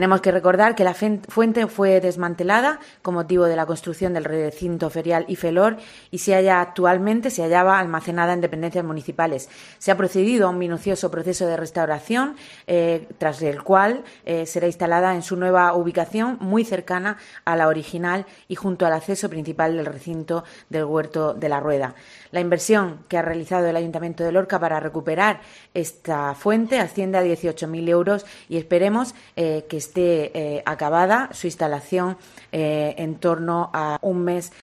Isabel Casalduero, edil de Fomento del Ayuntamiento de Lorca